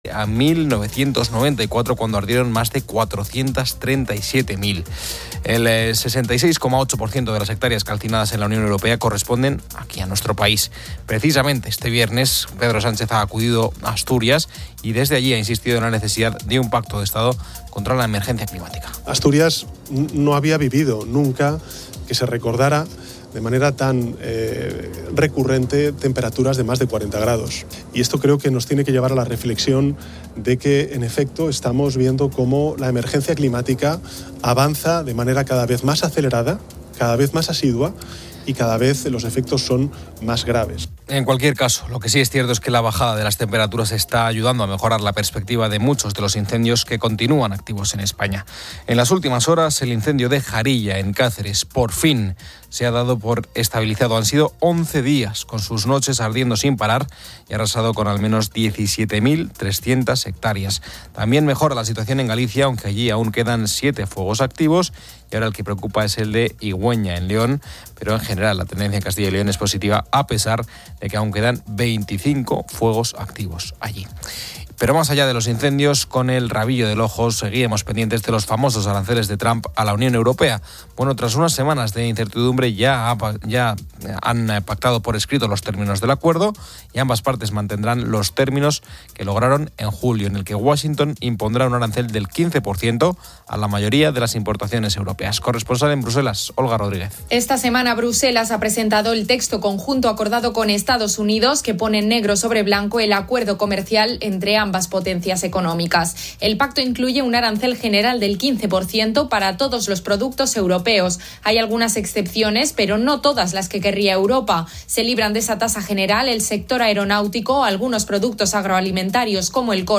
Finalmente, se escuchan **mensajes de oyentes** de diversos sectores (policías, camioneros, taxistas, sanitarios, etc.) que agradecen la compañía del programa en sus jornadas laborales.